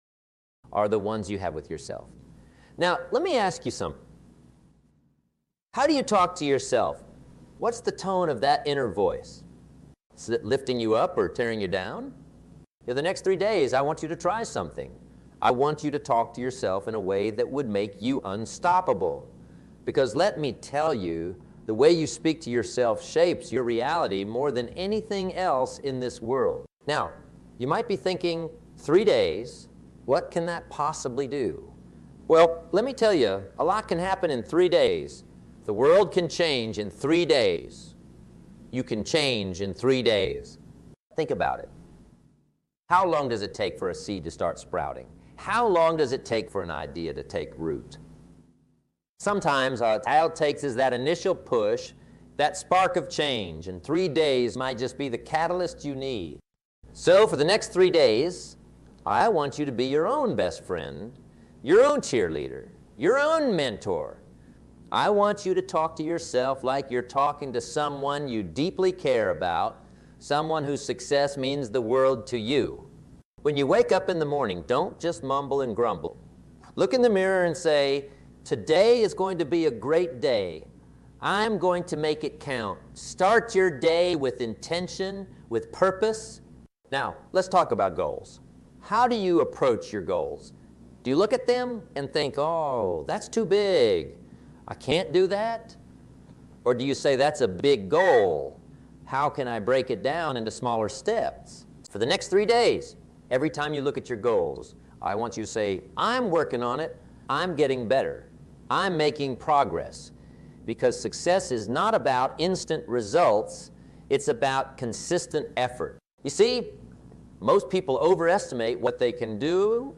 In this powerful talk, you’ll practice speaking to yourself like someone you love.